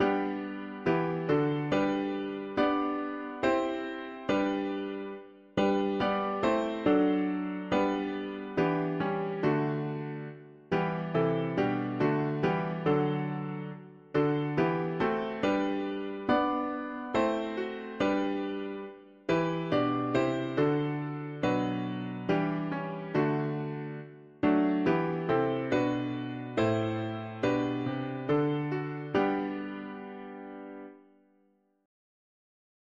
Lyrics: Now all the woods are sleeping, the night and stillness creeping o’er city, field, and beast; but thou, my heart, awake be, with pray’rful thanks, att… english secular 4part evening
Tune: O WELT, ICH MUSS DICH LASSEN
Key: G major